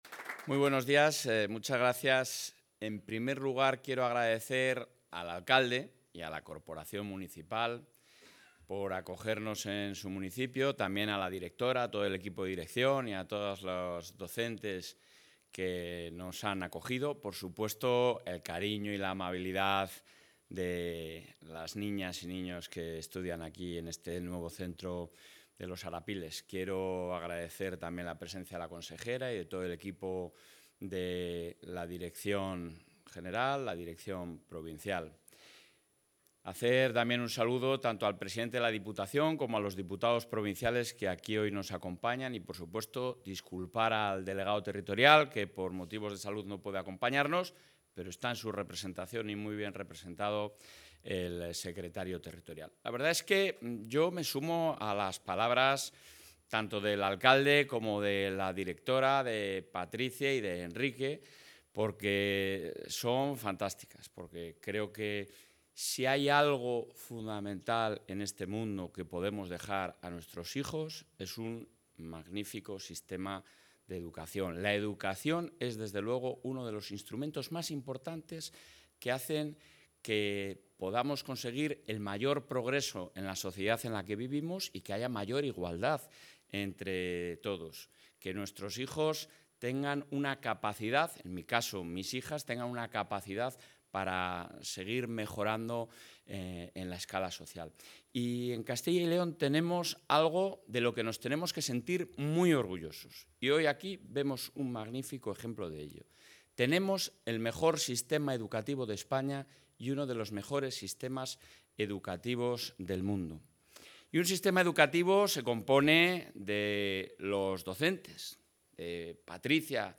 Intervención del presidente de la Junta.
El presidente de la Junta de Castilla y León, Alfonso Fernández Mañueco, ha visitado hoy el nuevo CEIP CRA 'Los Arapiles', ubicado en la localidad salmantina de Aldeatejada.